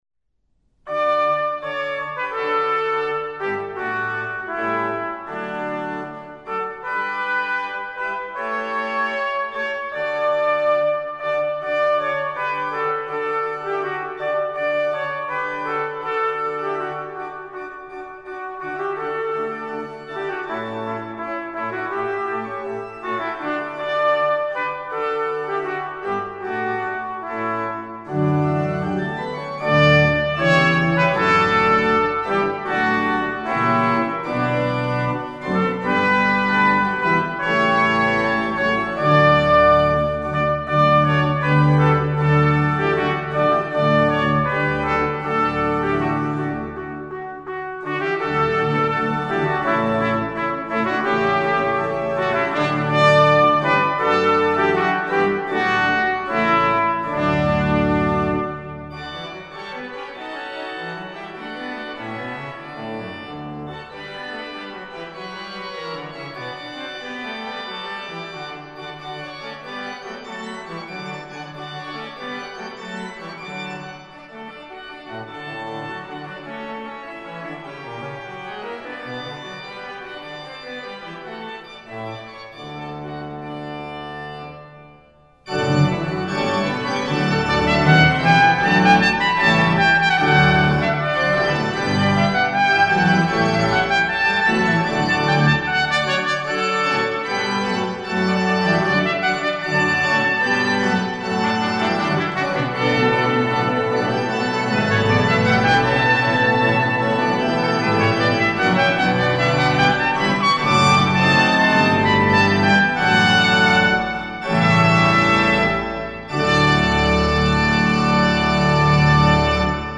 Voicing: TR W/org